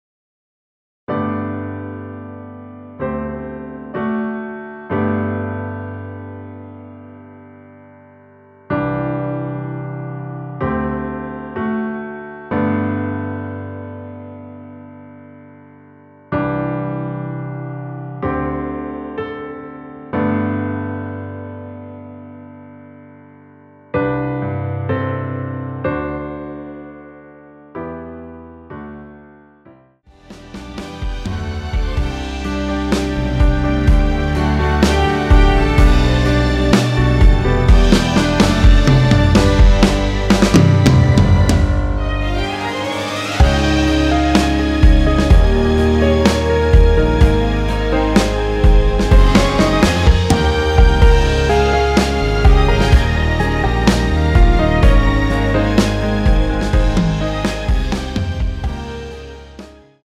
전주 없이 시작하는 곡이라 전주 만들어 놓았습니다.(미리듣기 확인)
원키에서(-2)내린 MR입니다.
앞부분30초, 뒷부분30초씩 편집해서 올려 드리고 있습니다.